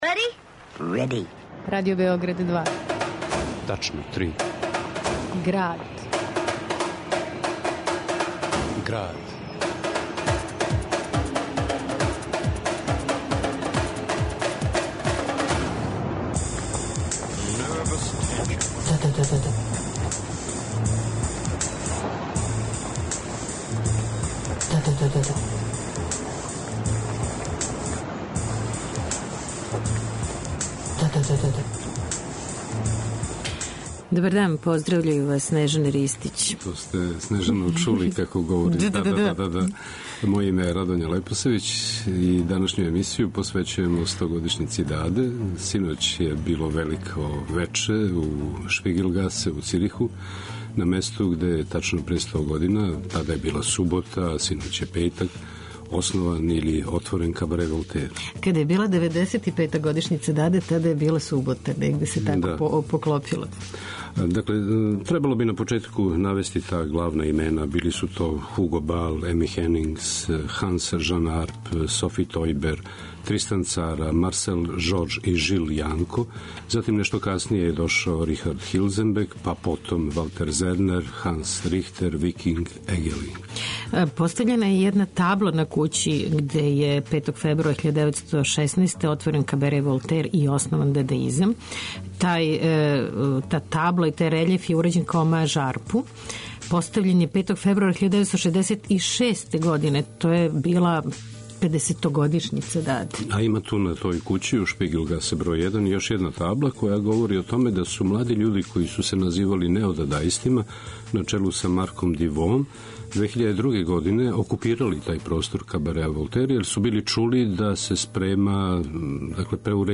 уз десетоминутни радијски фичер ДаДа 100, са архивских или актуелних снимака говориће бројни дадаисти, постдадаисти, неодадаисти и остали